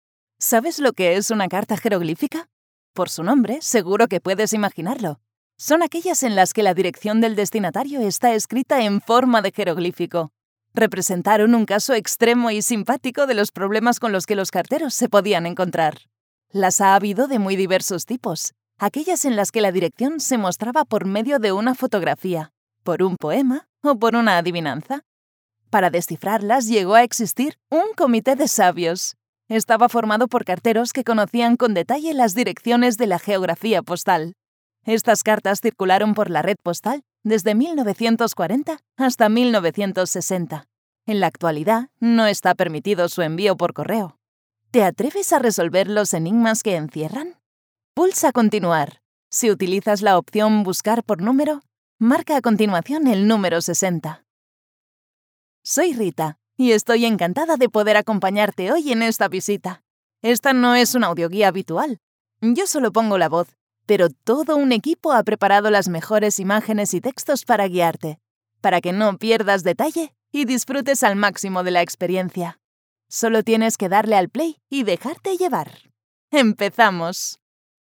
Warm, Soft, Natural, Friendly, Young
Audio guide